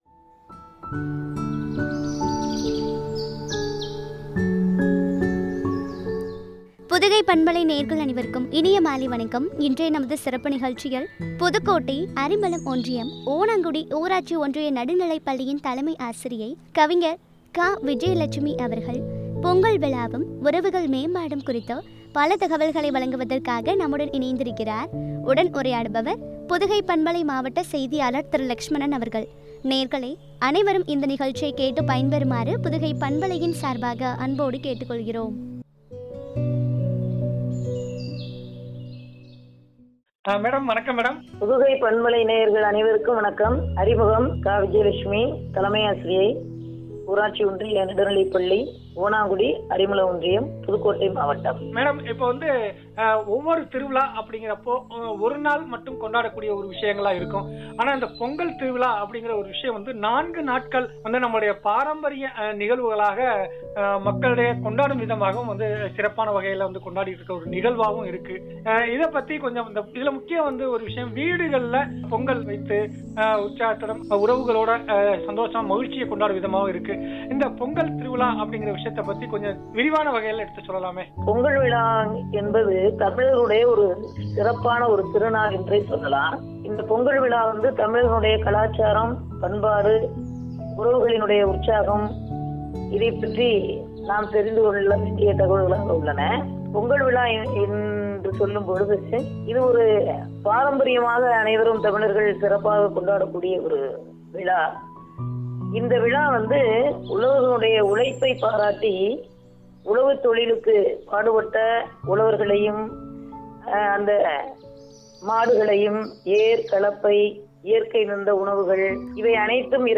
உறவுகள் மேம்பாடும் என்ற தலைப்பில் வழங்கிய உரையாடல்.